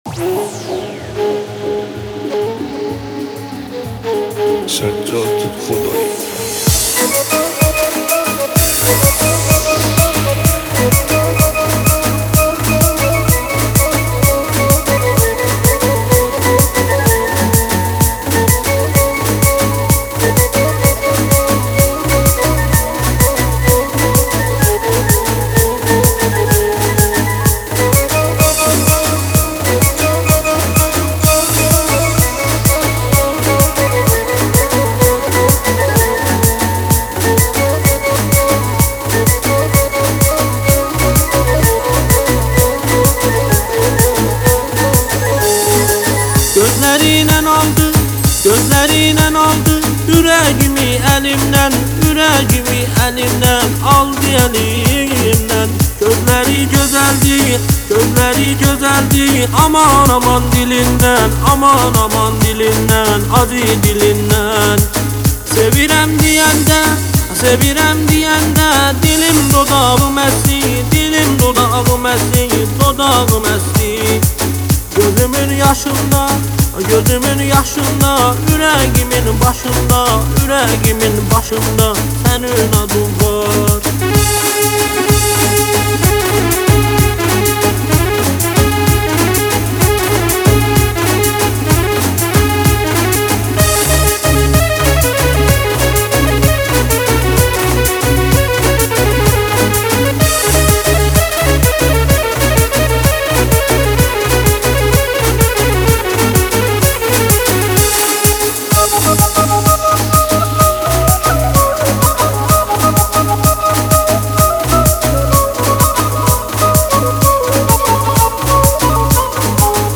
دانلود آهنگ ترکی